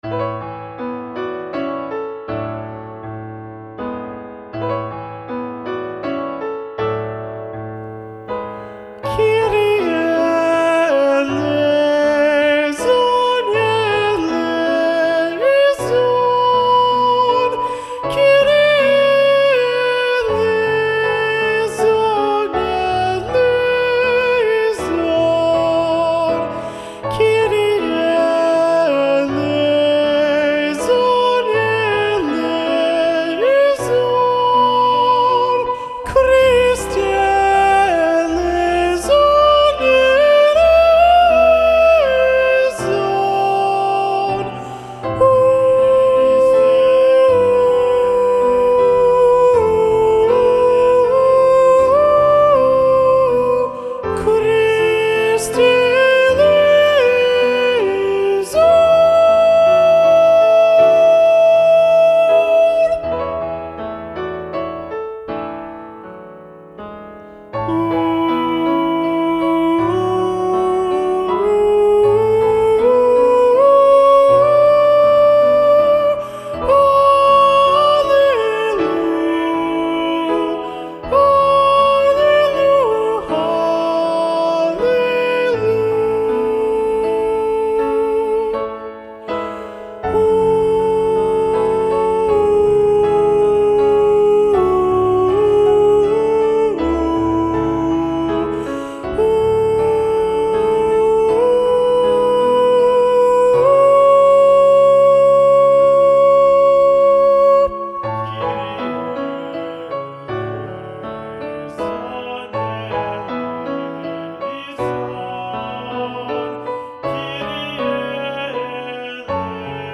Lo ULTIMO sopranos
Kyrie-Missa-Festiva-SATB-Soprano-Predominant-John-Leavitt-1.mp3